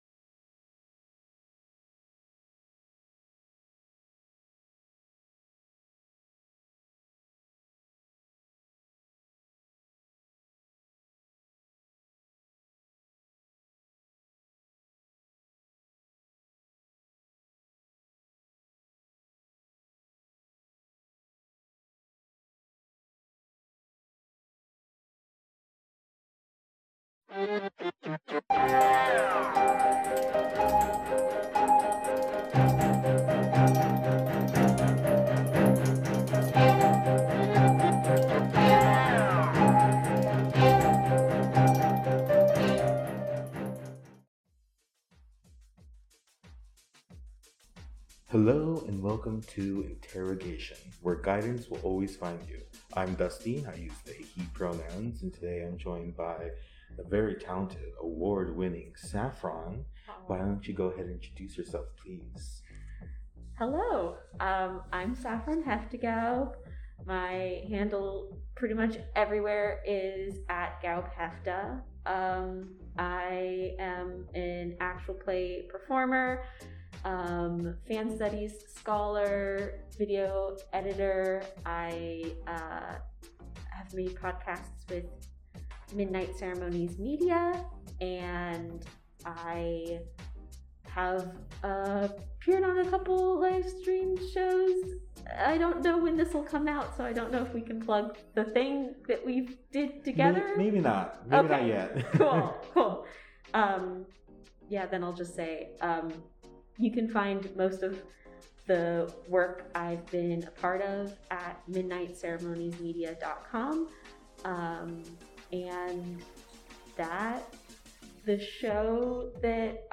This week join us for a special in person recording!